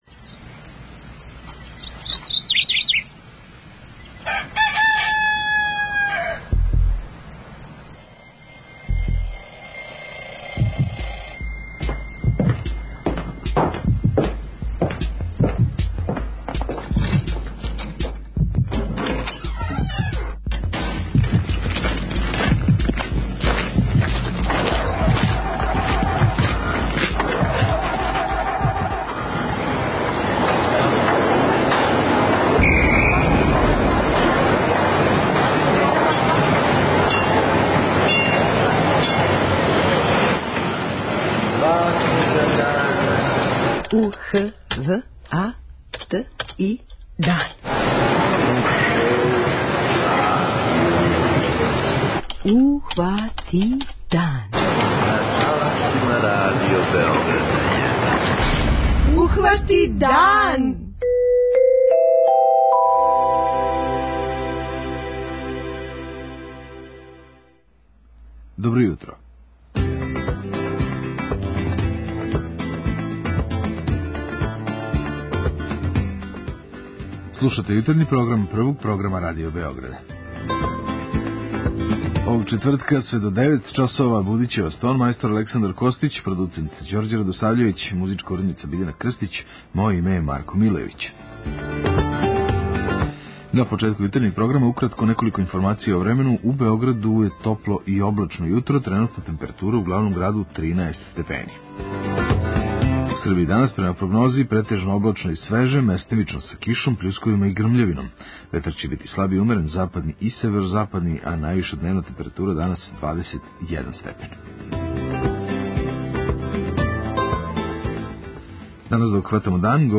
преузми : 21.57 MB Ухвати дан Autor: Група аутора Јутарњи програм Радио Београда 1!